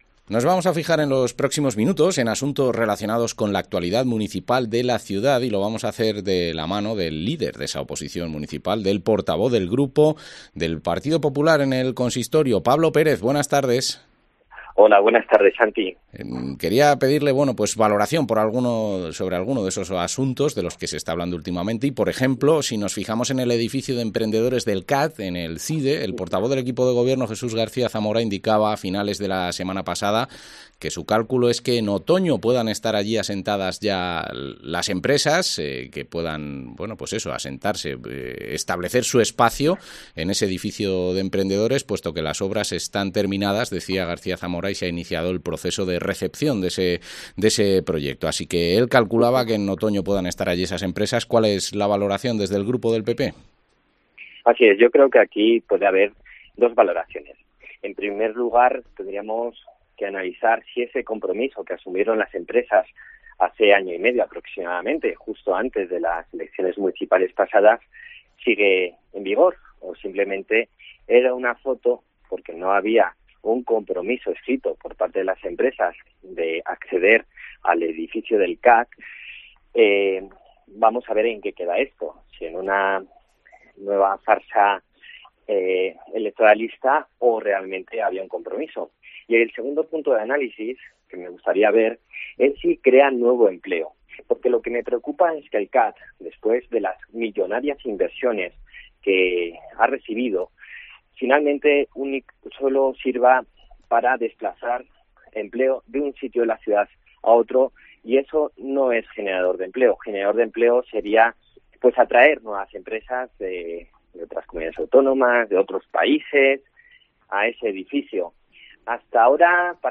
Entrevista al portavoz municipal del PP, Pablo Pérez